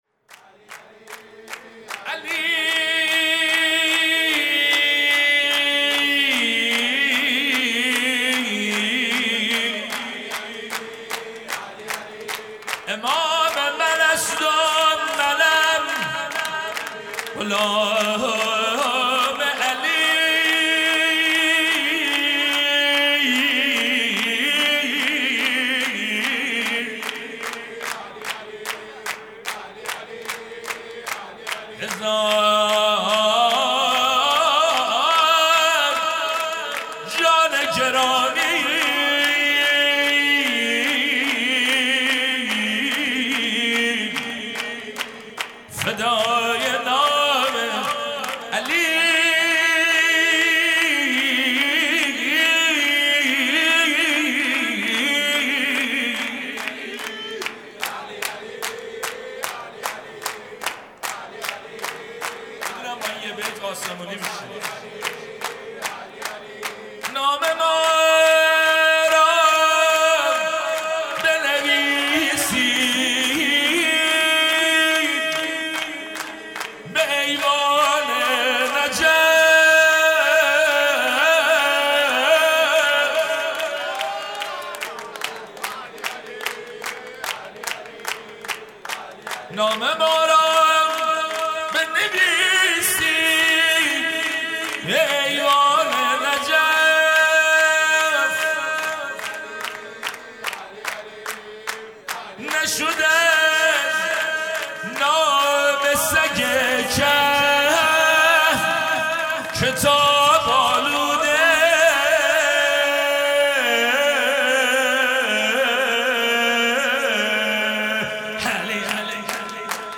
مدح
ولادت امام حسن عسکری (ع) | ۲۴ آذر ۱۳۹۷